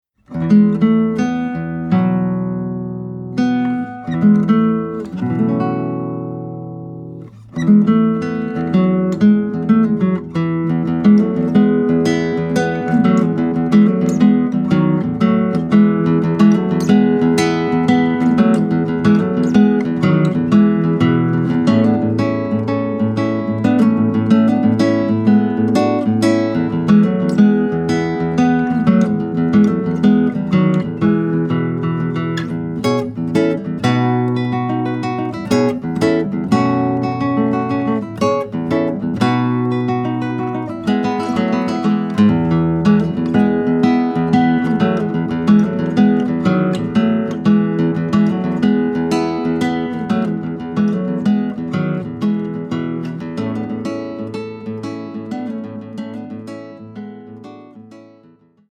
Audio Examples (solo guitar)
Contemporary Acoustic Fingerstyle